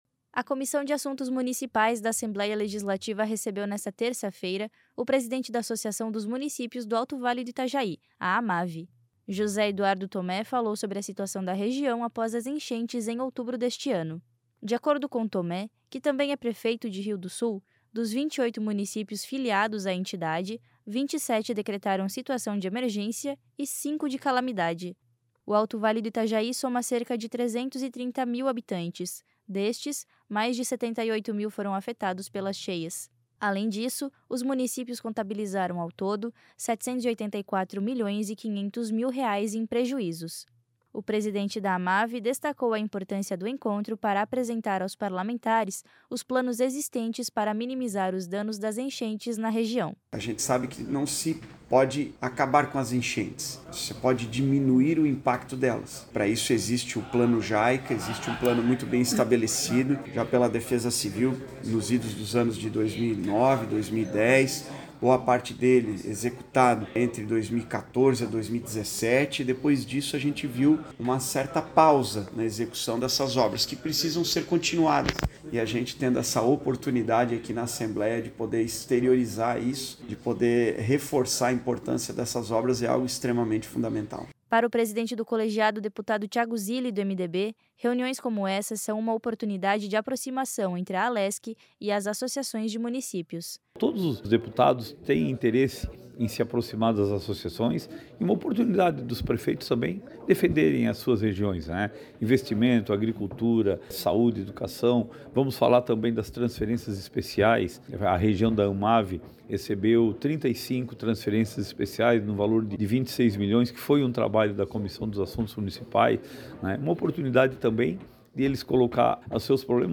Entrevistas com:
- José Eduardo Thomé, presidente da Amavi e prefeito de Rio do Sul;
- deputado Tiago Zilli (MDB), presidente da comissão.